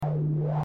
tractorbeam.mp3